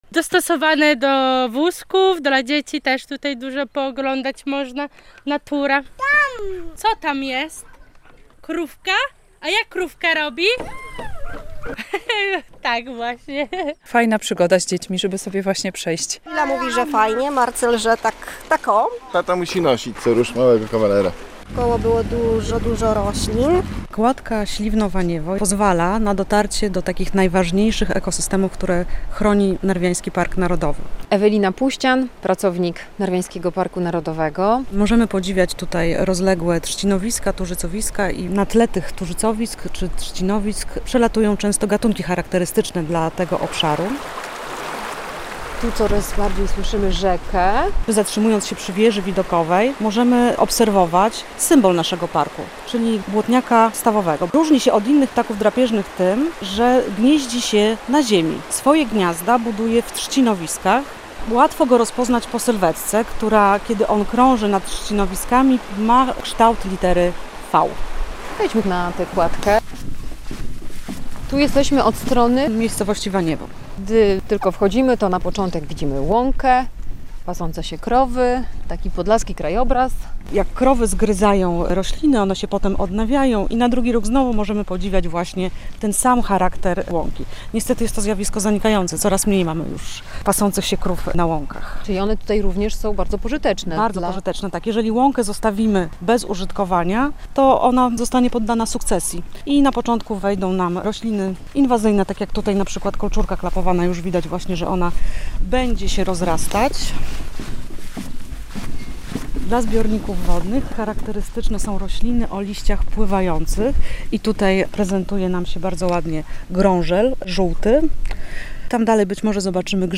Kładka Waniewo - Śliwno to doskonała atrakcja dla całej rodziny - relacja